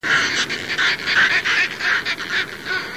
Fulmar - Fulmarus Glacialis
głosy